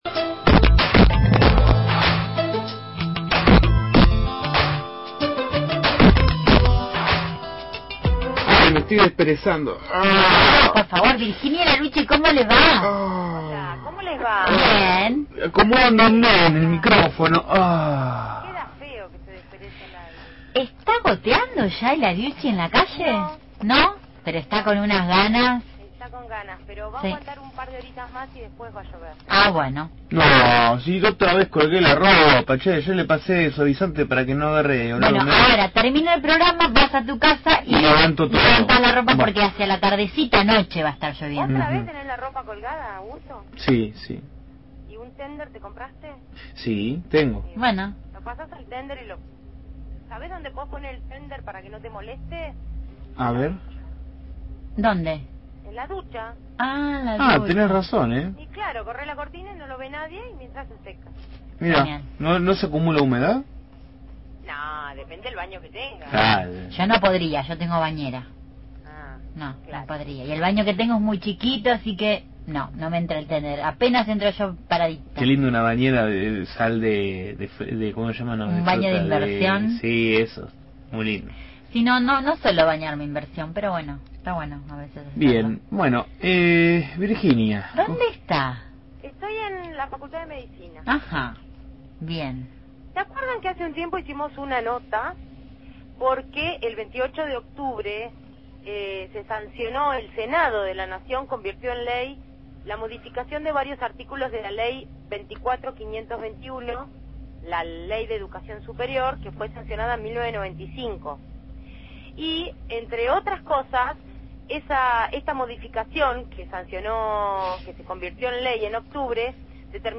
MÓVIL/ Facultad de Medicina sobre ingreso irrestricto – Radio Universidad
desde la Facultad de Medicina con la propuesta que el Centro de Estudiantes (Viento de Abajo) y la FULP le presentaron a las autoridades de dicha casa de estudios sobre la ley de gratuidad que se publicó en el boletín oficial.